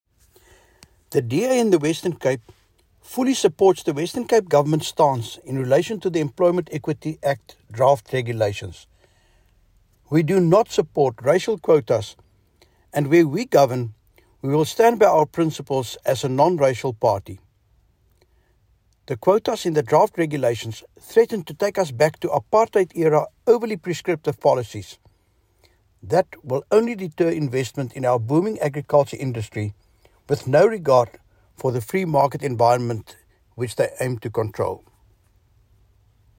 English soundbites from MPP Andricus van der Westhuizen attached.